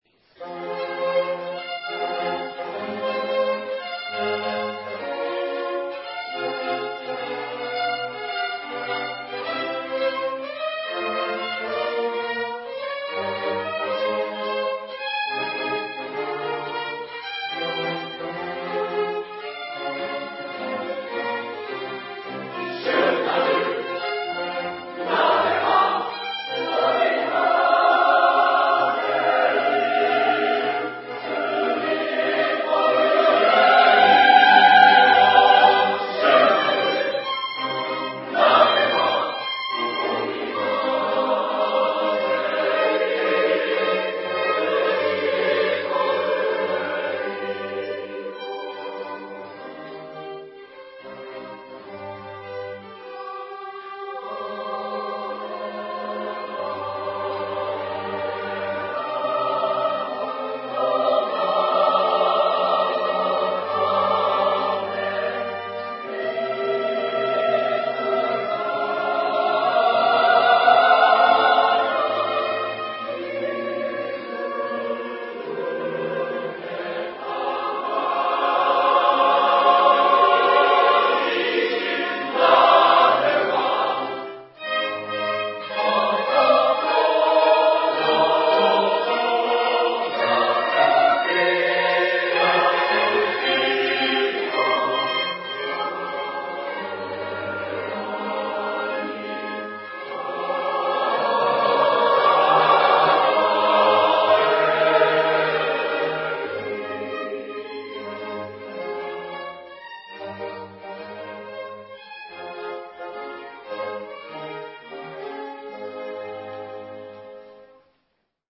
吉祥寺2002年公演録音